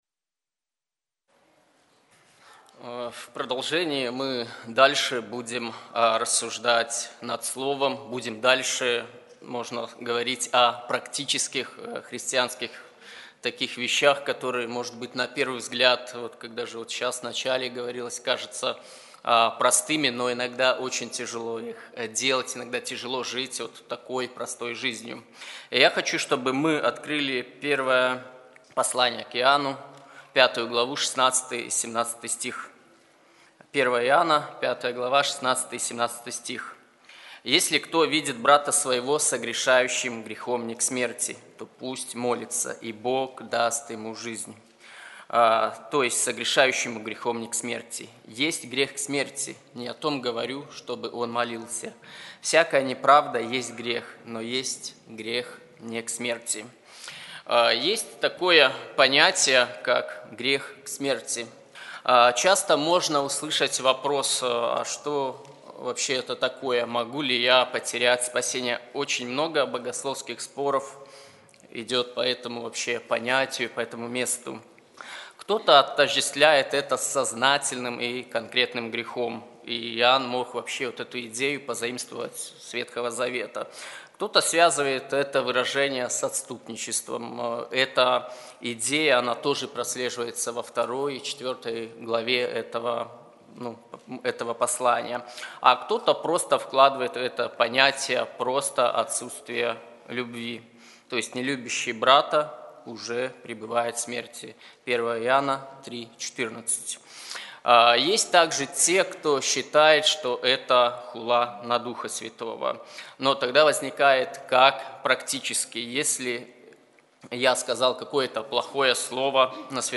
Проповедует
Церковь евангельских христиан баптистов в городе Слуцке